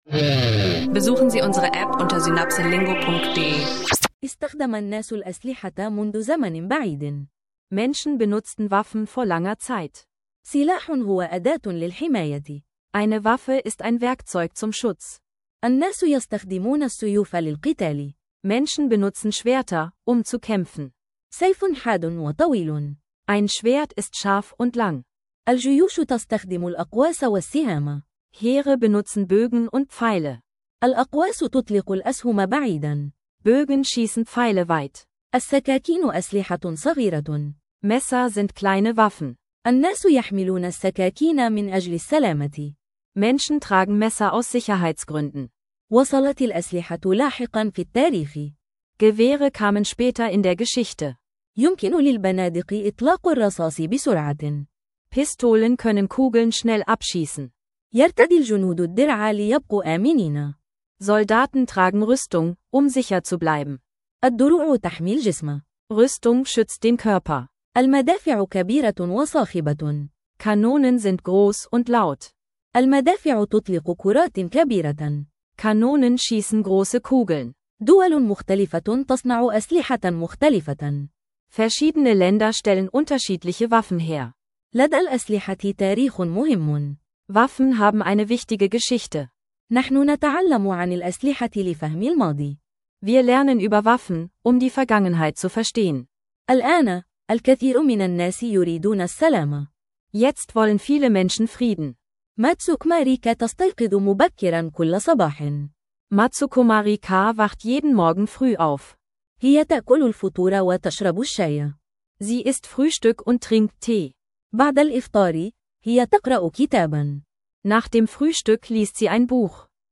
In dieser Folge hören Sie kurze, einfach erzählte Geschichten über Waffen, den Alltag von Marika, Sommerurlaub und Digitalisierung — perfekt zum Arabisch lernen mit Podcast. Enthält gezielte Arabisch Vokabeln und Phrasen zum Mitlernen, ideal zum Üben unterwegs und für Anfänger.